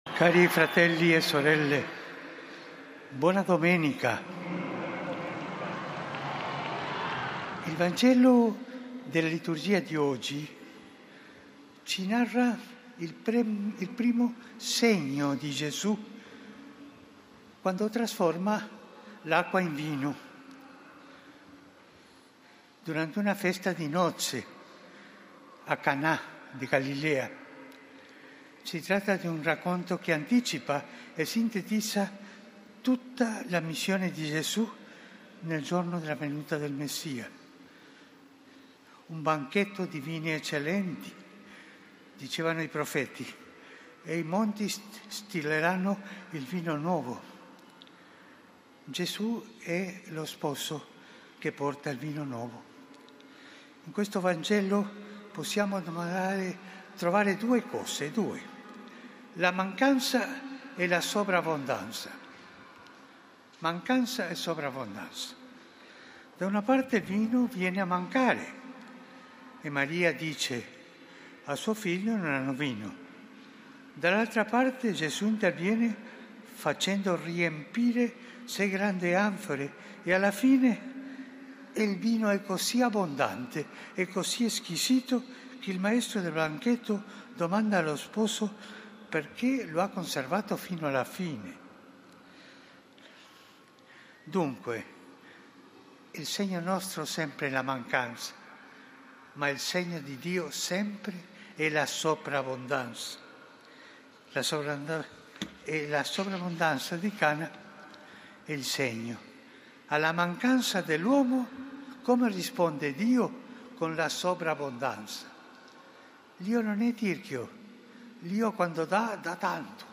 ANGELUS